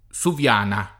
[ S uv L# na ]